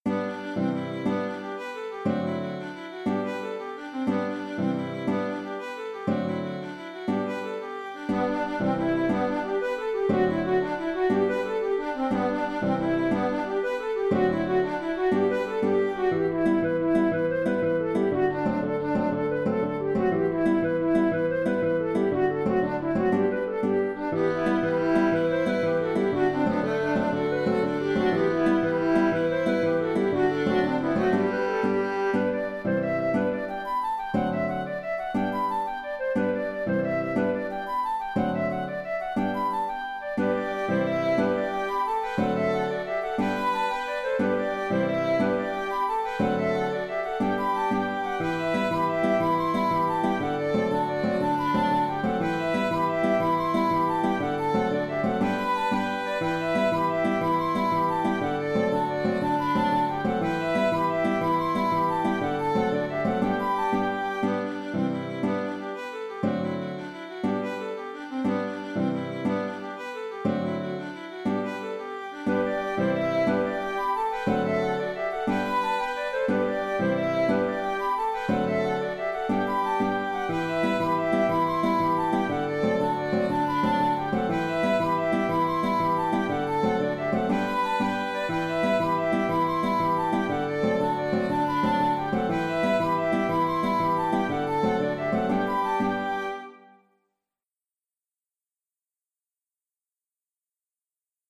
Tripping of the stair (Jig) - Musique irlandaise et écossaise
En Sol majeur (G), il faut la jouer à l’octave pour pouvoir y ajouter un contrechant sur les deux parties du thème, ce qui complique le jeu de l’accordéon diato. Dans mon groupe, j’ai donc eu recours à la flûte et au violon pour la jouer à l’octave. Pour le reste, il faut surtout y mettre de l’énergie..
Jig irlandaise